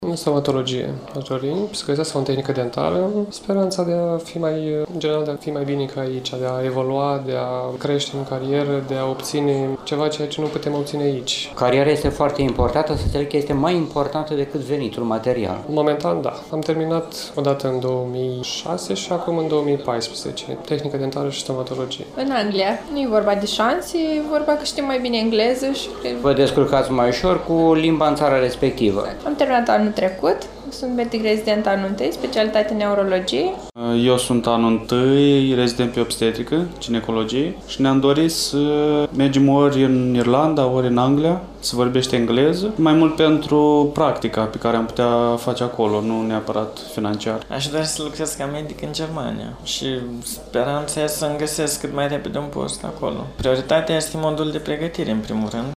10-apr-ora-16-vox-pop.mp3